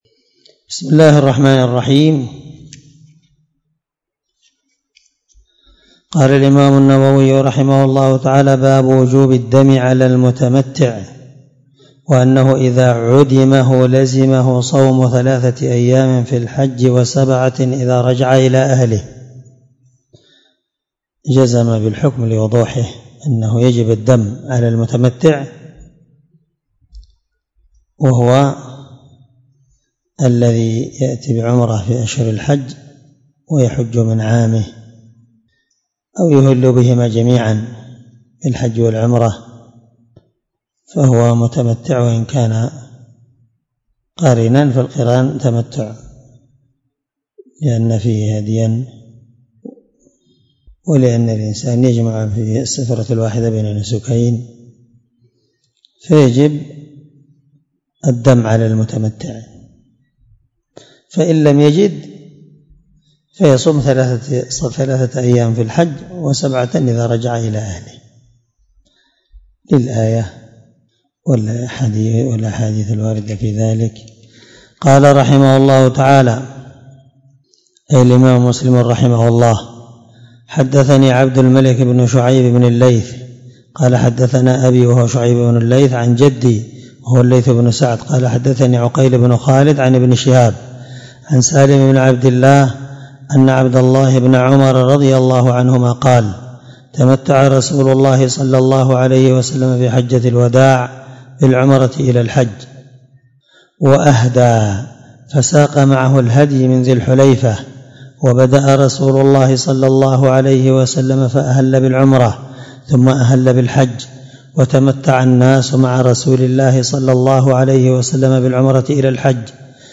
الدرس
دار الحديث- المَحاوِلة- الصبيحة.